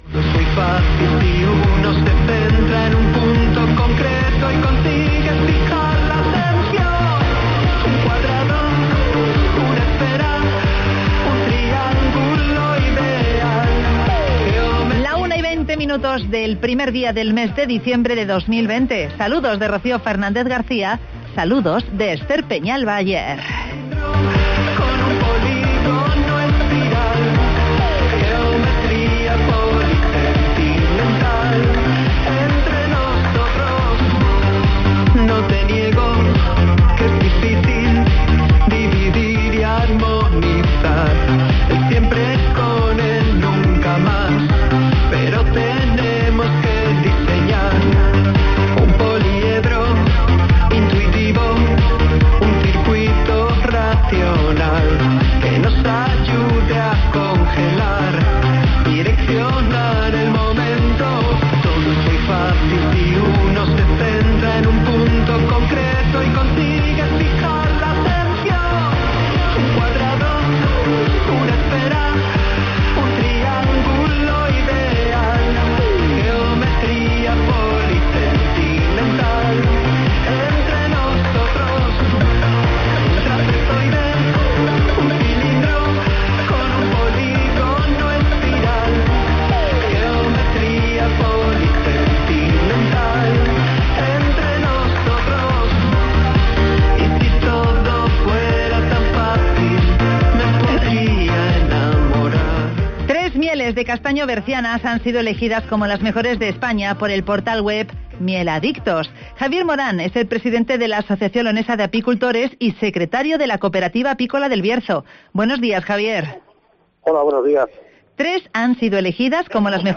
Tres mieles de castaño bercianas, elegidas como las mejores de España por el portal web Mieladictos (Entrevista